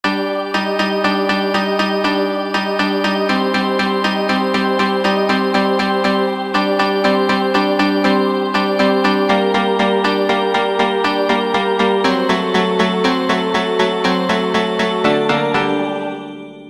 без слов
инструментальные